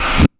066 FlangeSnrRvs.wav